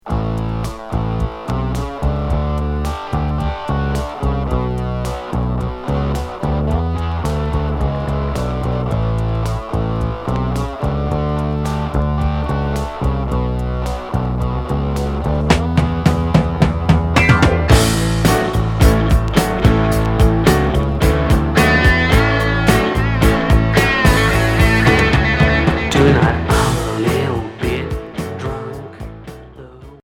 Rock glam